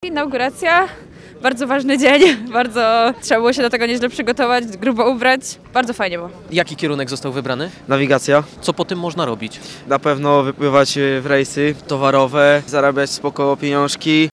Polska staje się portową potęgą na Bałtyku i wygrywa konkurencję z innymi portami – powiedział w sobotę w Gdyni premier Donald Tusk. Zwrócił też uwagę na przedsięwzięcia energetyczne powstające na morzu i w jego sąsiedztwie, jak farmy wiatrowe i elektrownię jądrową.
Premier, razem z ministrem infrastruktury Dariuszem Klimczakiem, wziął w sobotę udział w inauguracji roku akademickiego Uniwersytetu Morskiego w Gdyni, na pokładzie „Daru Młodzieży” przy molo.